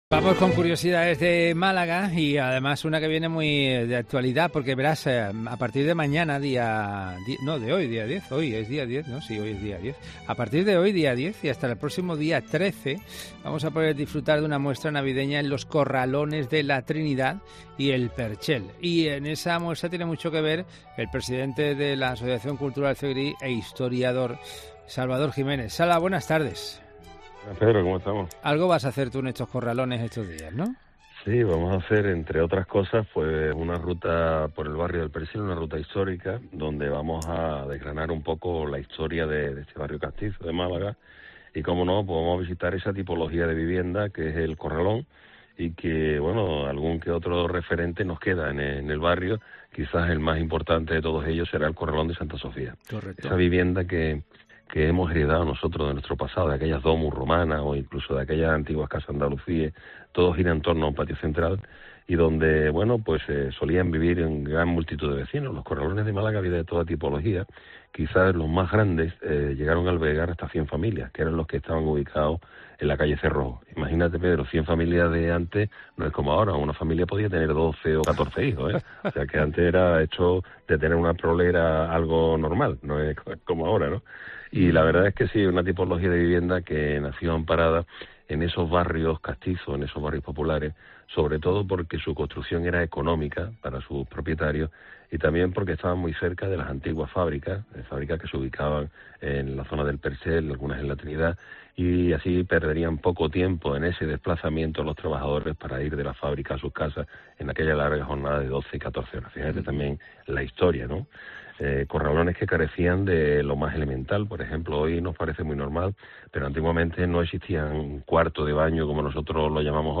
Historiador